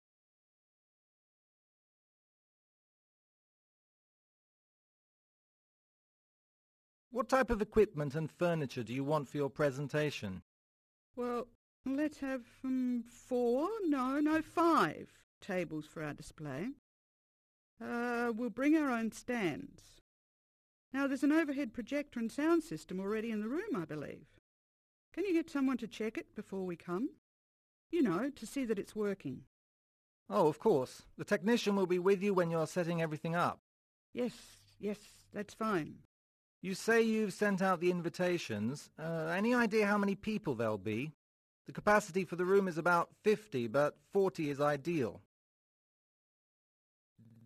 4. Аудирование диалога по теме «Подготовка к проведению презентации компании», ответы на вопросы по прослушанному.